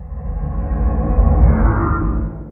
elder_idle3.ogg